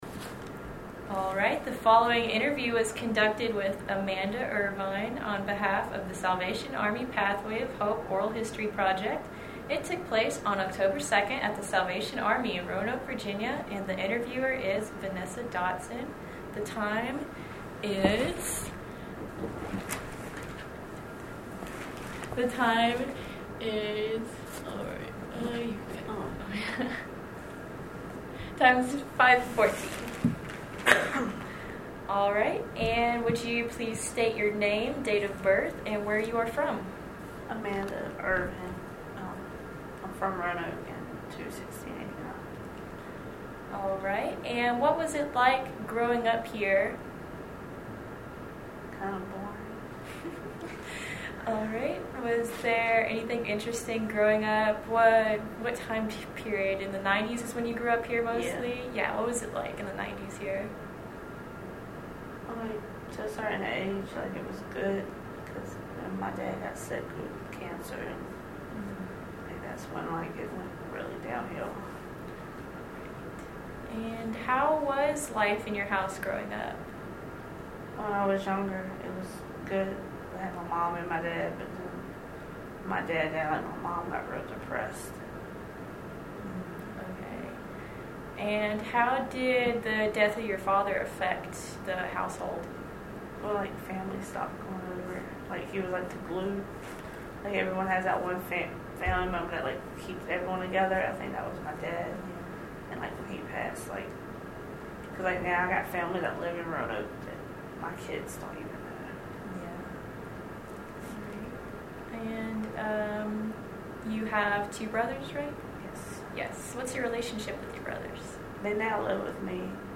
Oral History Interview
Location: Salvation Army